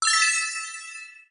addScore.mp3